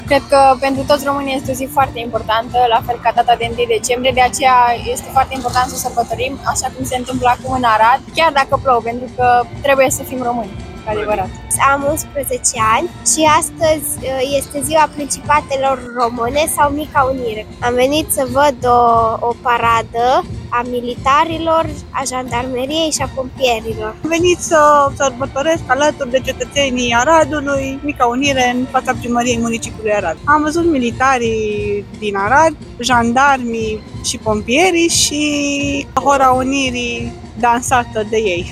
01-voxuri-Unire-AR.mp3